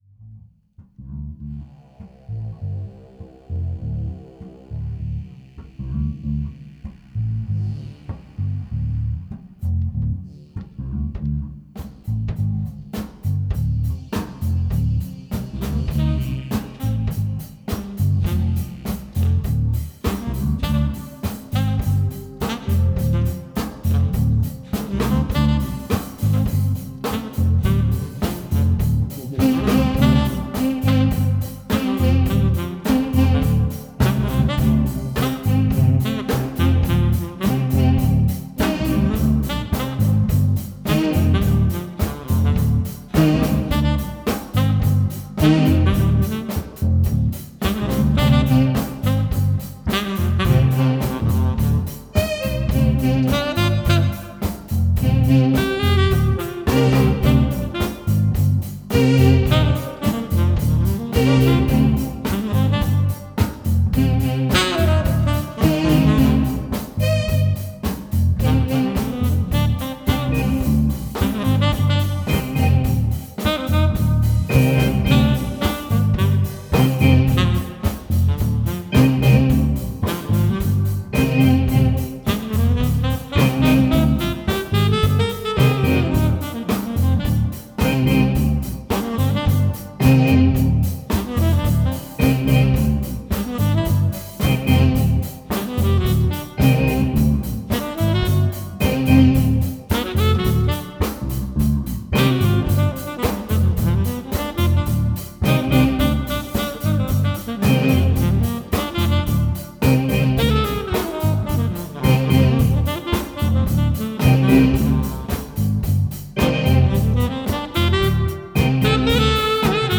Tempo: 60 bpm / Datum: 14.03.2017